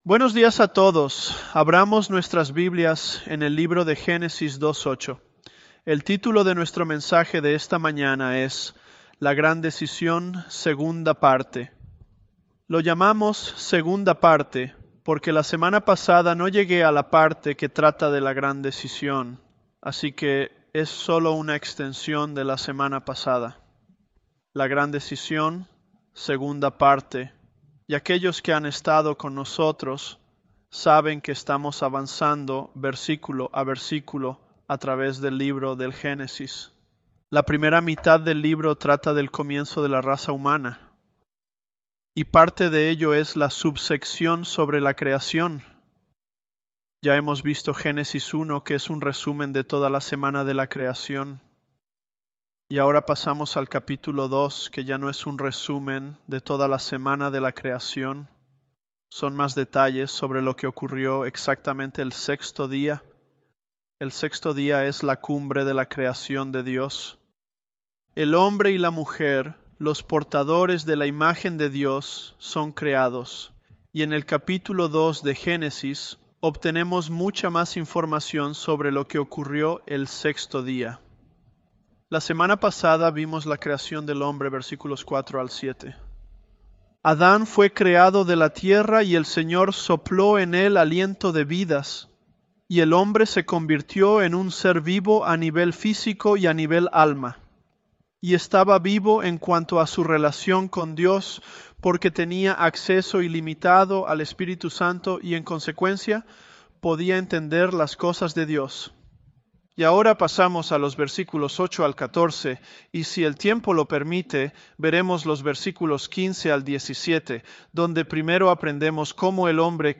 Sermons
ElevenLabs_Genesis-Spanish009.mp3